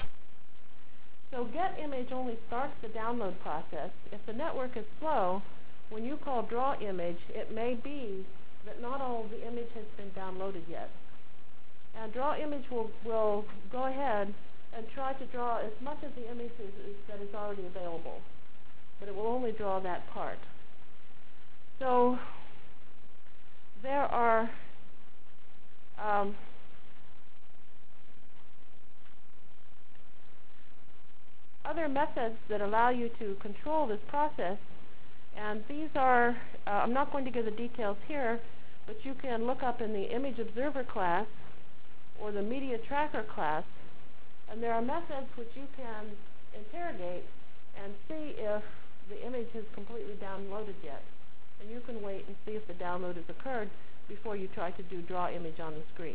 Delivered Lecture for Course CPS616